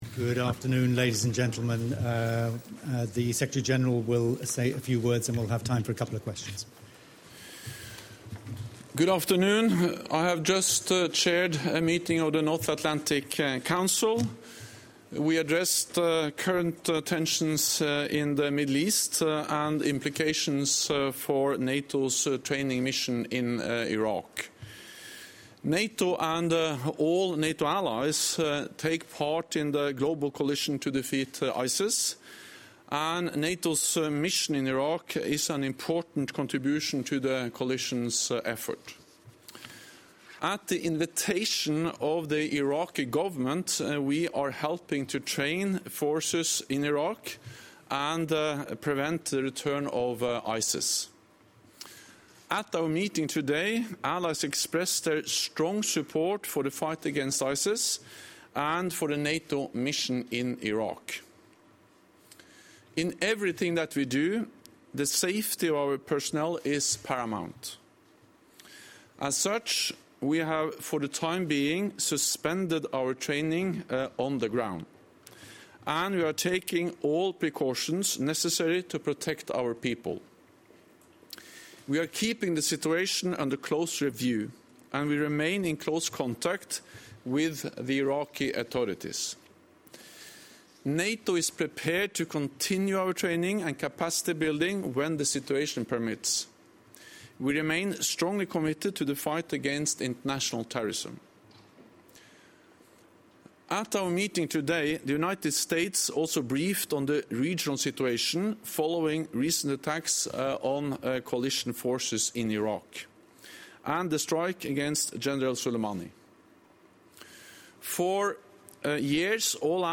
Press point
by NATO Secretary General Jens Stoltenberg following a meeting of the North Atlantic Council